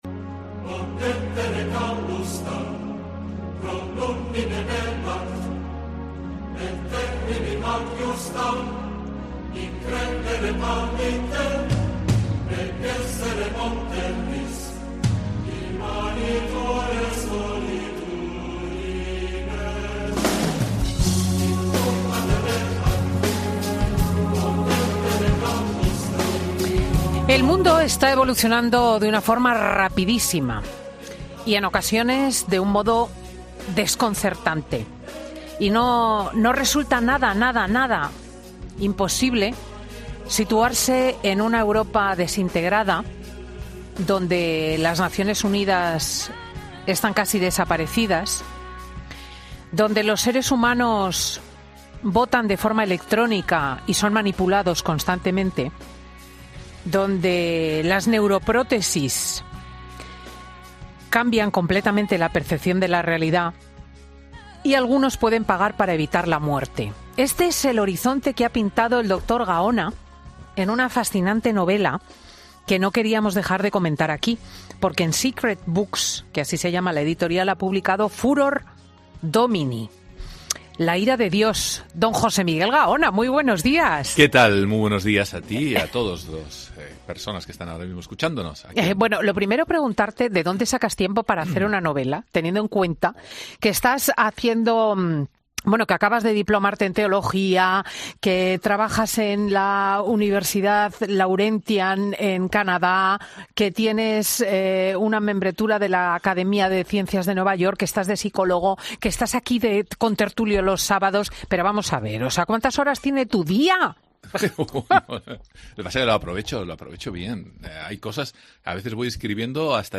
El neuropsiquiatra forense pasa por Fin de Semana con Cristina para presentar su nuevo libro y explicar por qué, a pesar de su contenido, es positivo